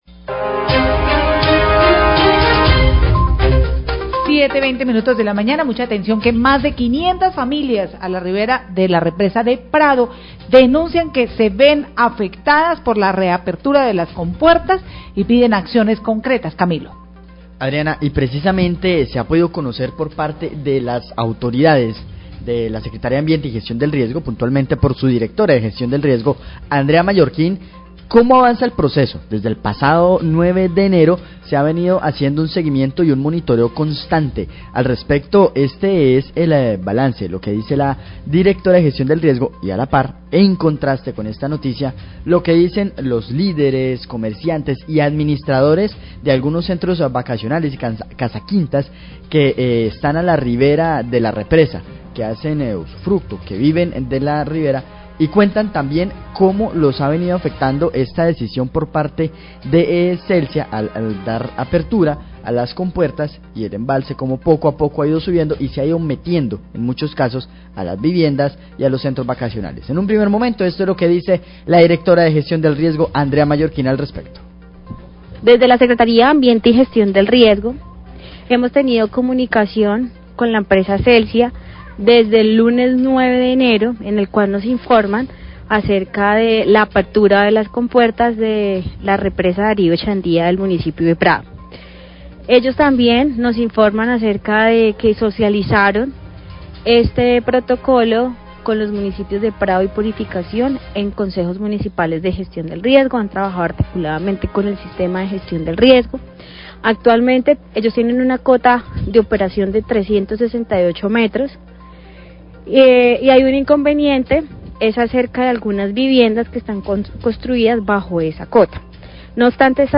Radio
La Secretaria de Gestión del Riesgo del Tolima, Andrea Marroquín, habla de la comunicación constante con Celsia durante el procesos de apertura controlada de compuertas de represa de Prado y la inundación de predios privados que se encuentran bajo la cota máxima de la represa.